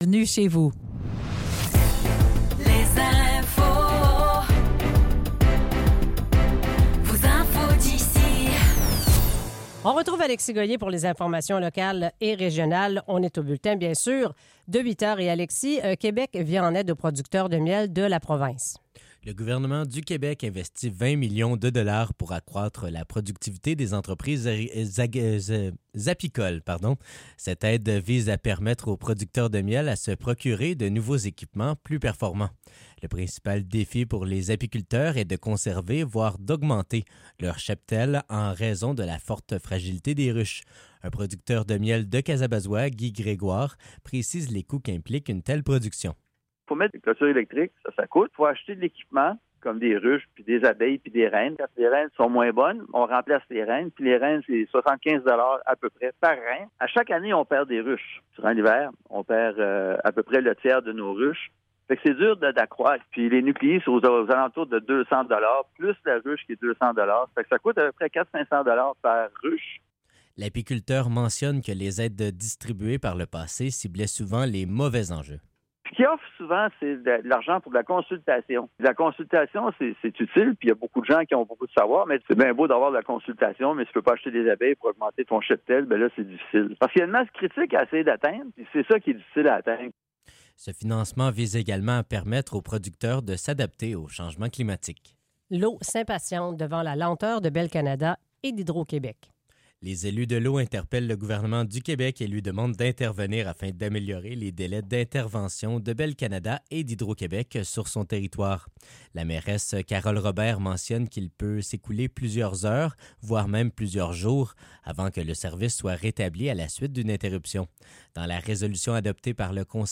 Nouvelles locales - 6 décembre 2024 - 8 h